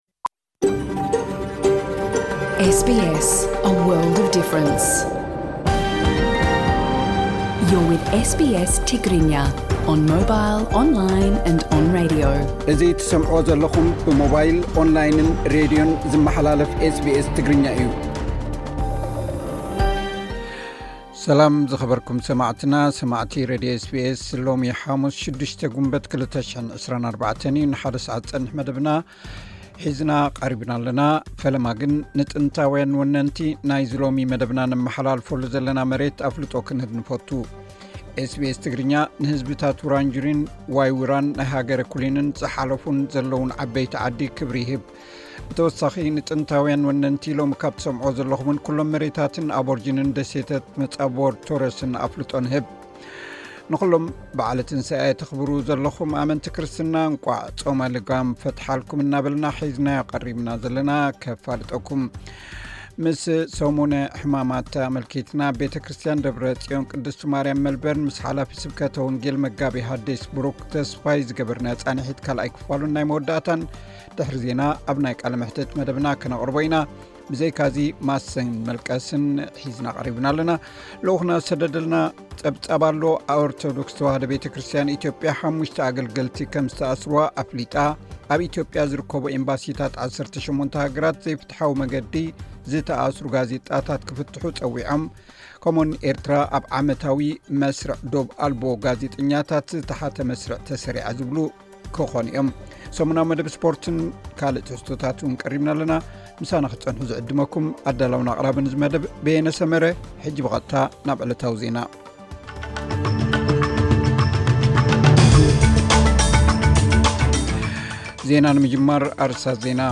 ድሕሪ ዜና ኣብ ናይ ቃለ መህትት መደብና ከነቕርቦ ኢና።